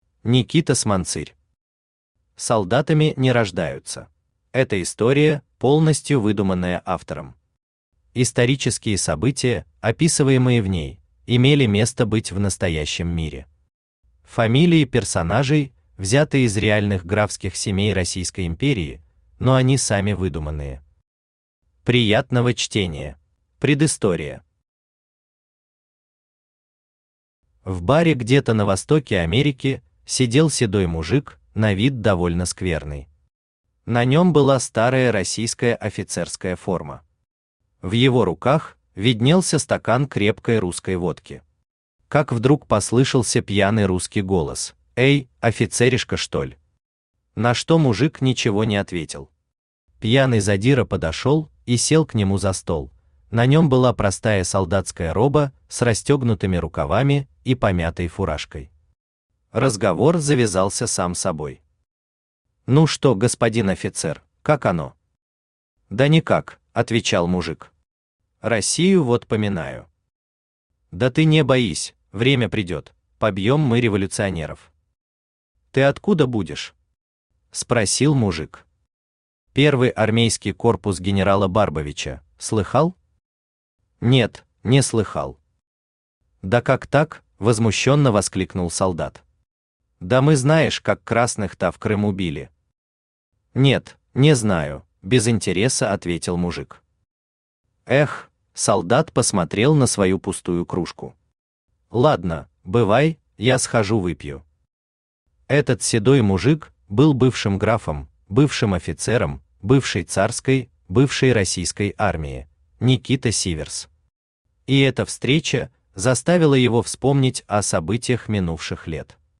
Aудиокнига Солдатами не рождаются… Автор Никита Ильич Сманцырь Читает аудиокнигу Авточтец ЛитРес.